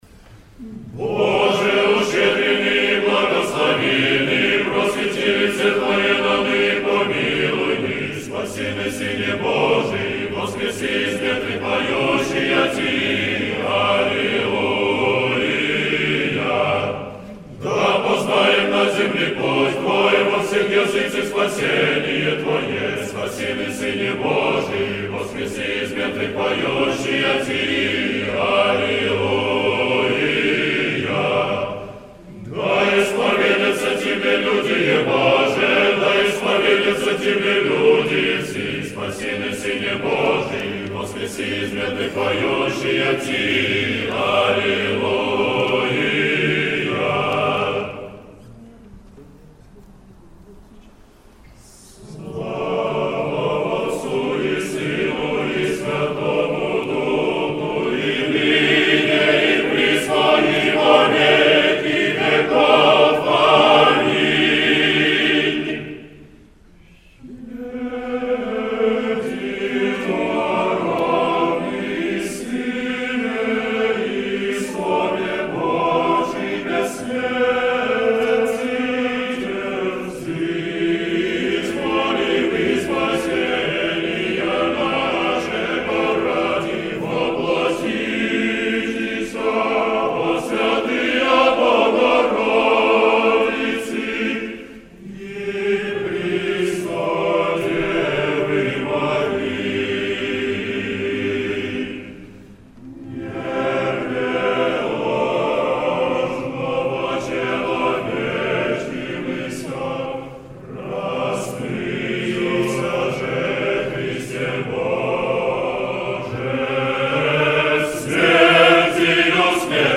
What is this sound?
The Paschal antiphons are sung: